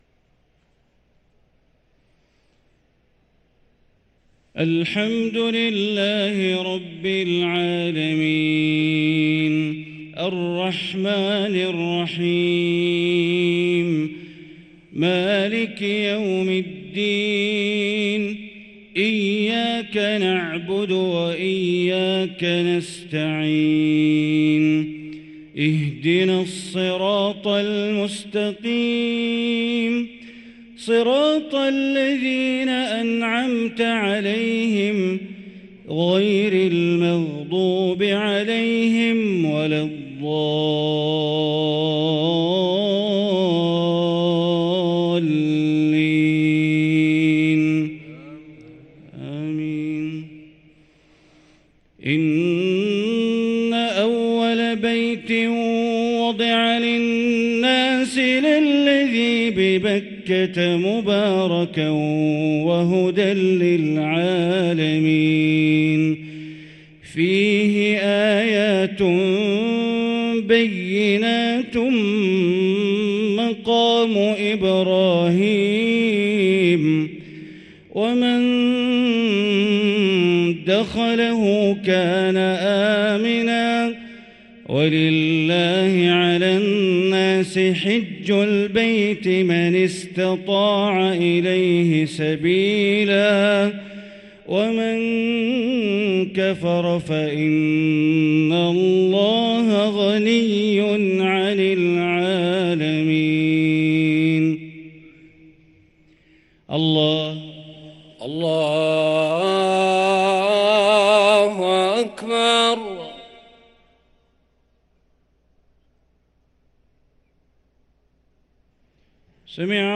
صلاة العشاء للقارئ بندر بليلة 9 رمضان 1444 هـ
تِلَاوَات الْحَرَمَيْن .